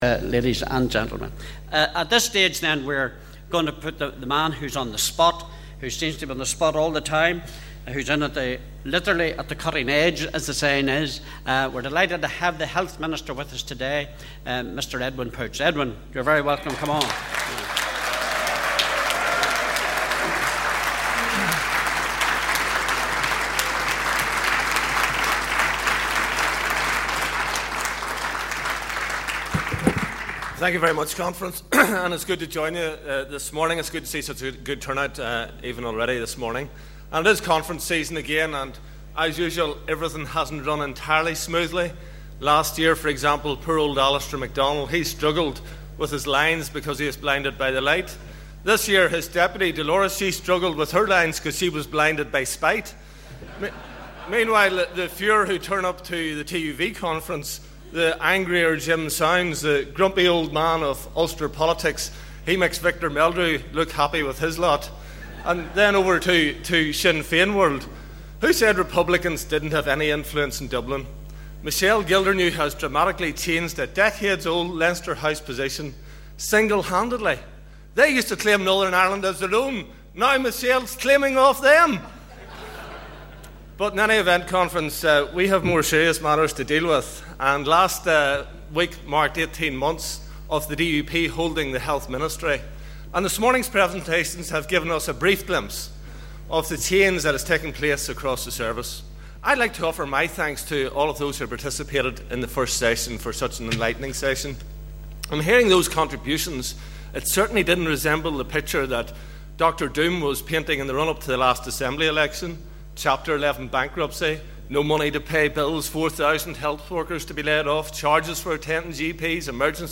Edwin Poots addressing DUP conference